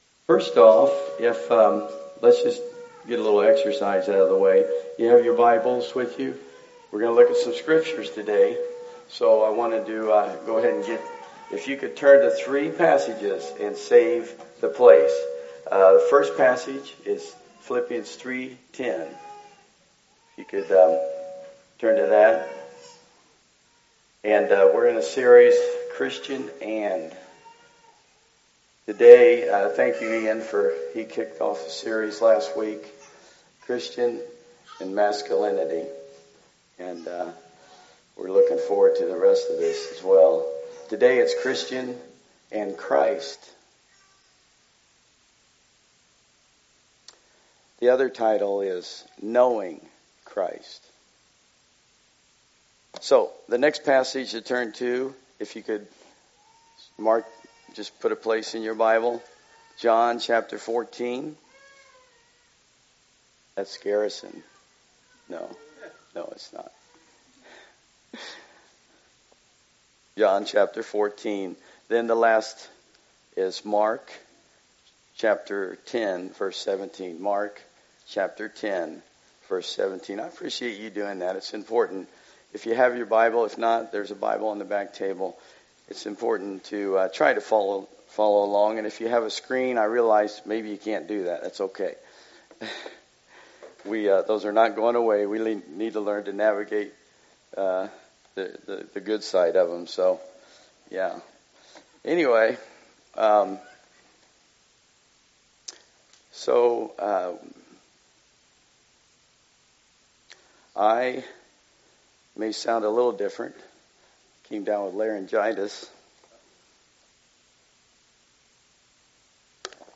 Passage: Phil 3:10 Service Type: Sunday Service Download Files Notes « The Christian and Biblical Masculinity The Christian And…